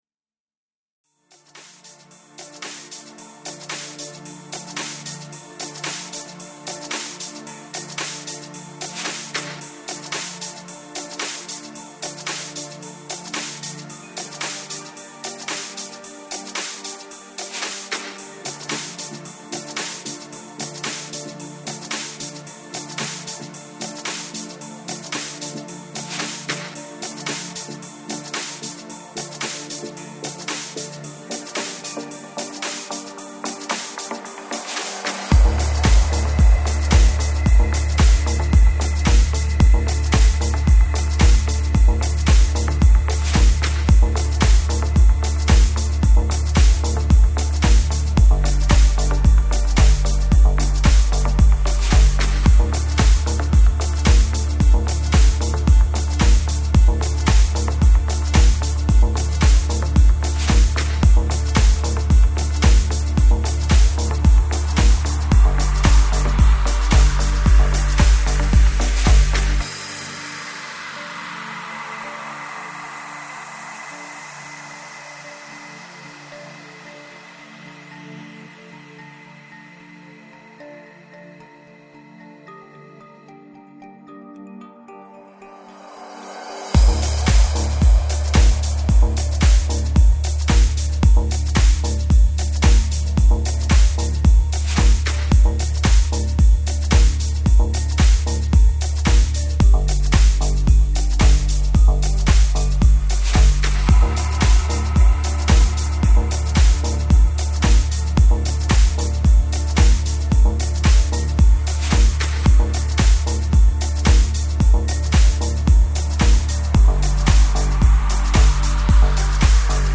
FLAC Жанр: Lounge, Chill Out Год издания